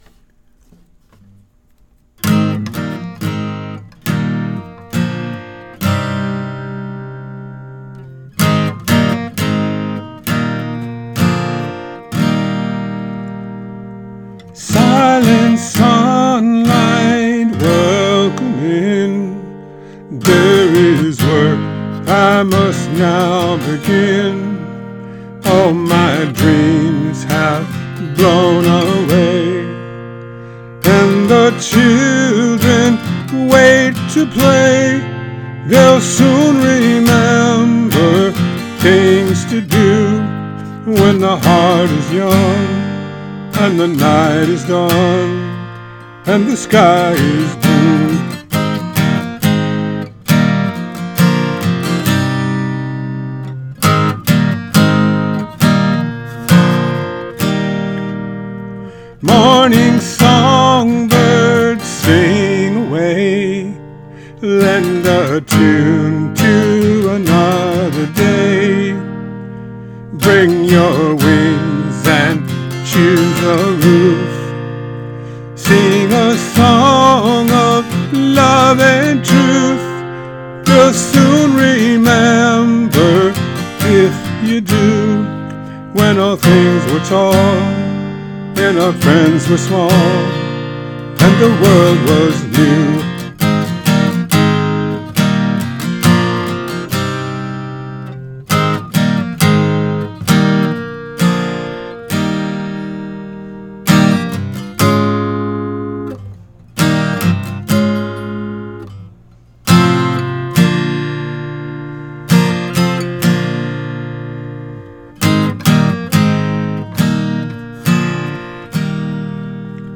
Here's my cover.